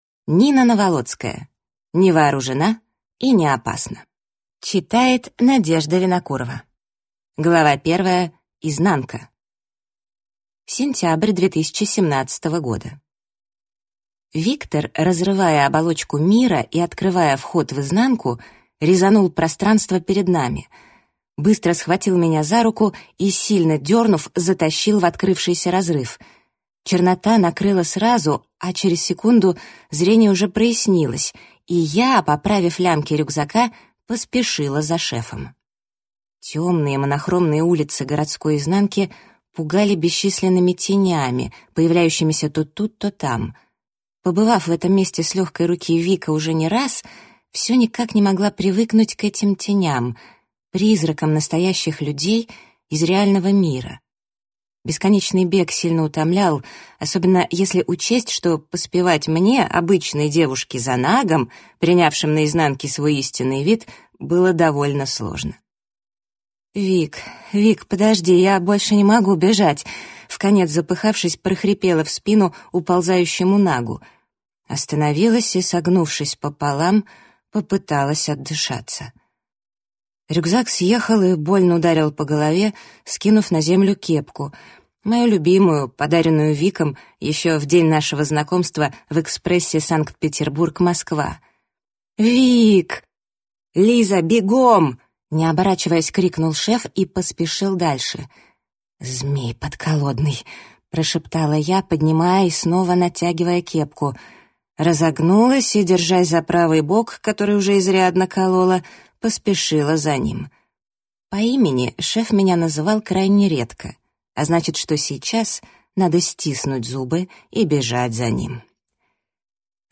Аудиокнига Не вооружена и не опасна | Библиотека аудиокниг
Прослушать и бесплатно скачать фрагмент аудиокниги